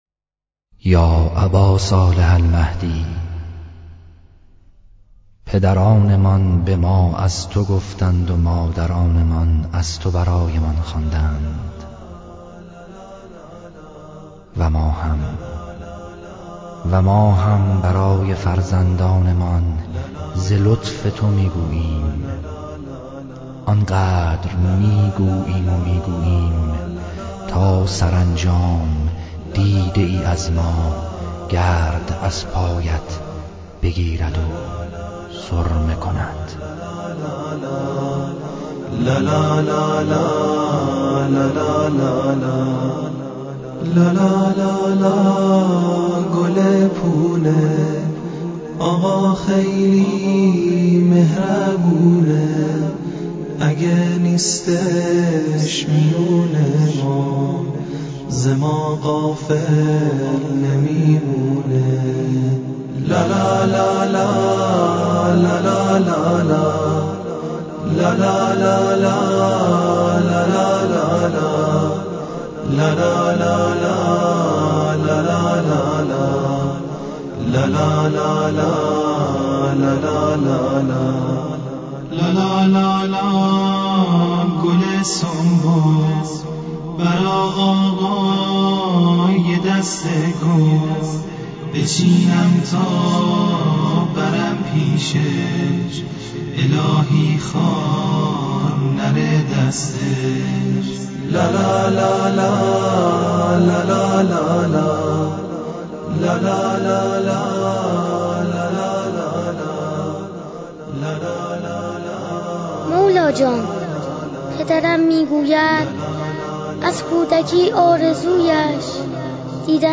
لالایی کودکانه - لالایی امام زمان عجل الله تعالی فرجه الشریف